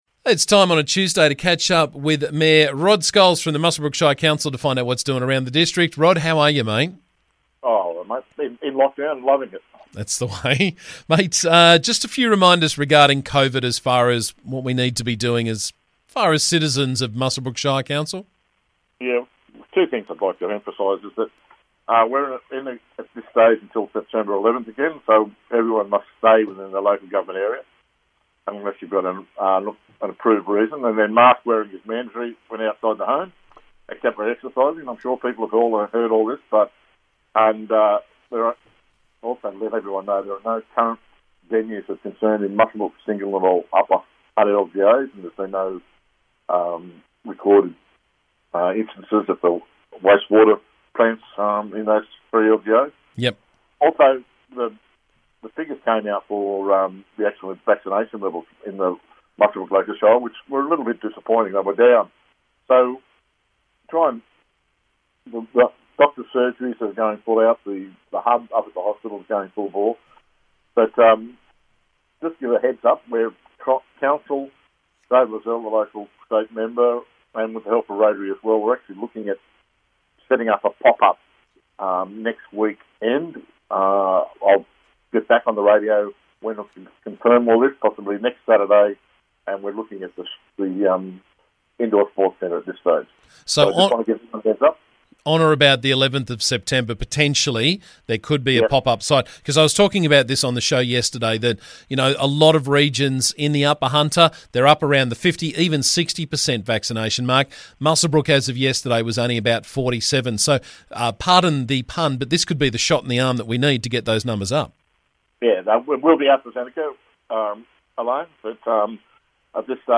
Muswellbrook Shire Council Mayor Rod Scholes joined me to talk about the latest from around the district.